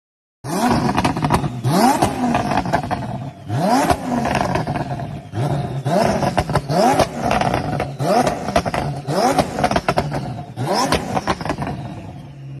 Звуки выхлопной системы